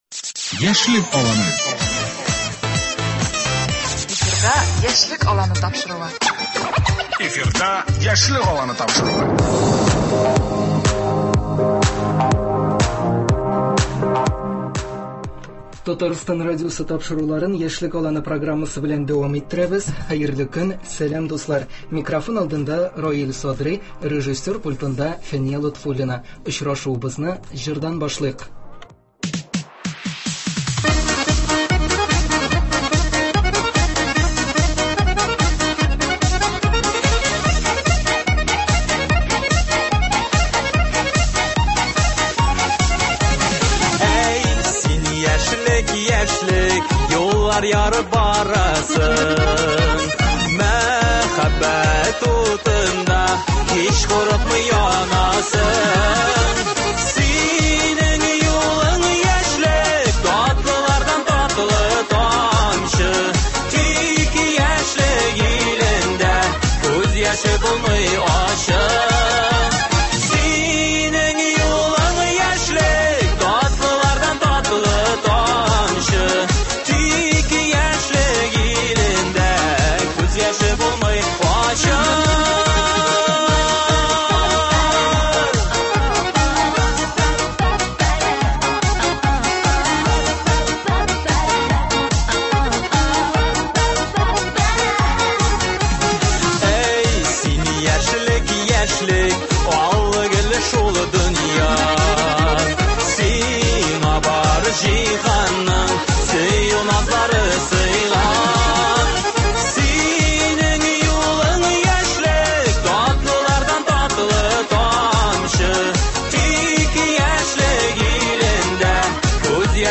Әңгәмә турыдан-туры эфирда барачак.